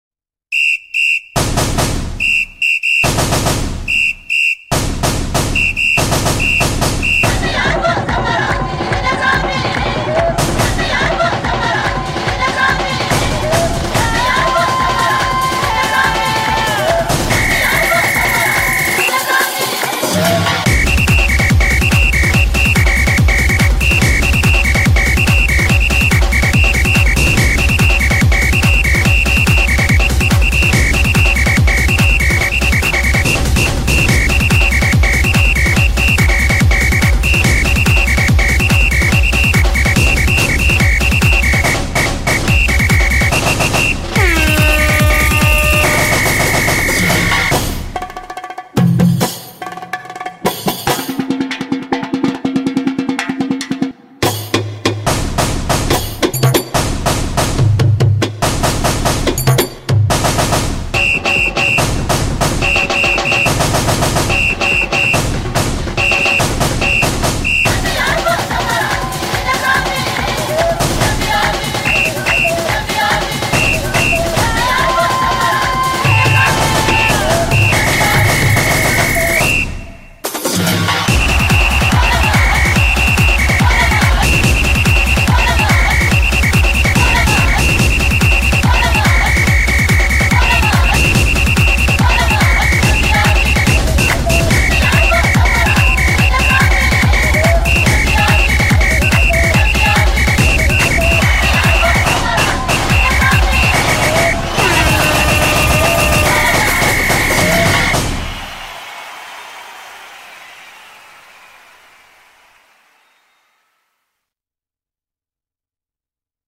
BPM143
Audio QualityPerfect (Low Quality)